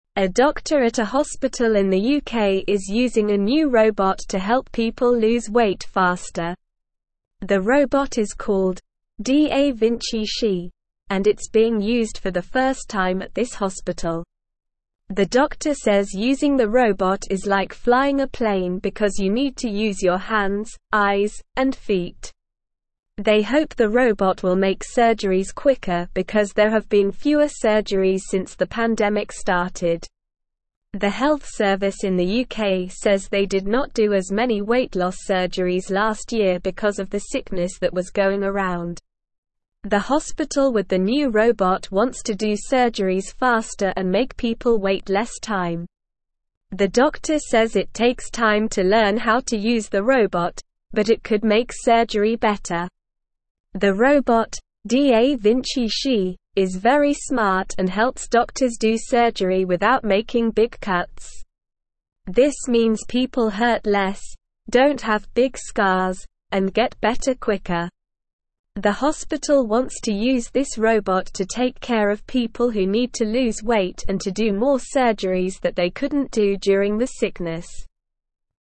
English-Newsroom-Lower-Intermediate-SLOW-Reading-New-Robot-Helps-Hospital-Do-More-Surgeries.mp3